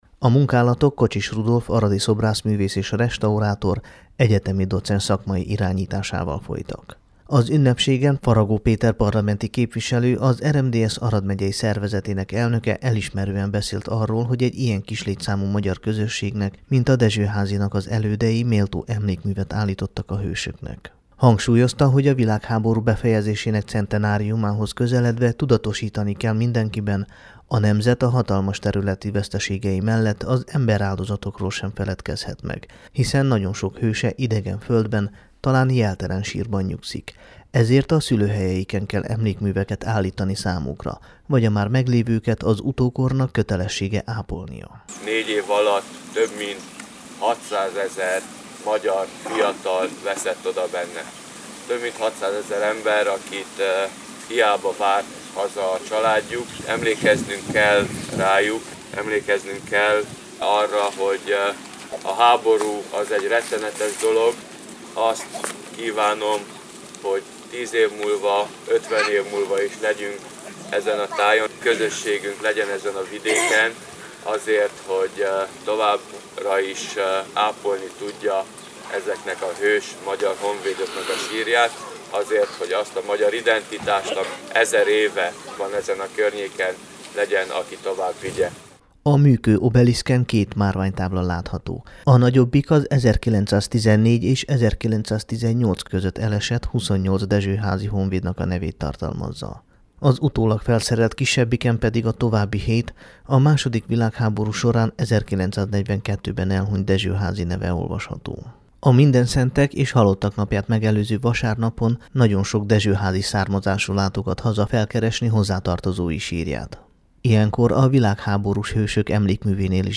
dezsohazi_vilaghaborus_emlekmu_avatasa.mp3